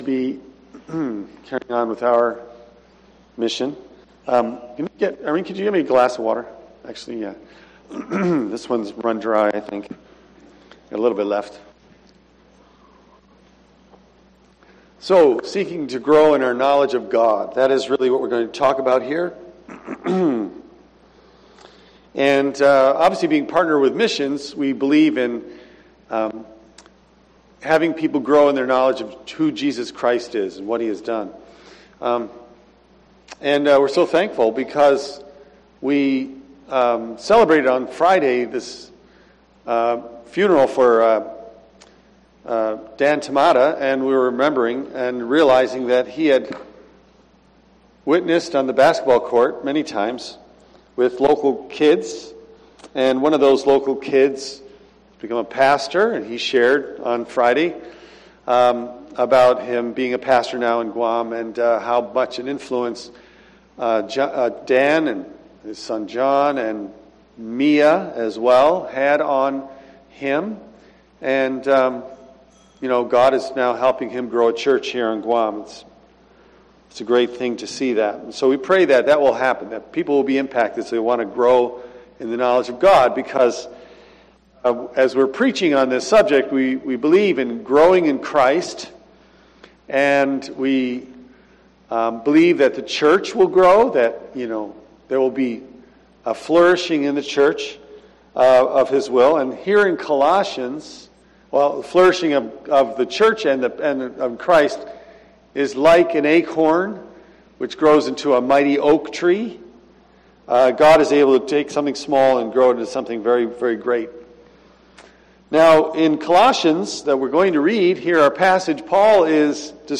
I am going to preach on growth in Christ and growth in the church for the next few weeks. Here is Colossians, Paul describes how another aspect of the Christian dynamic is to grow in knowledge and understanding of who Christ is and what He has done for us.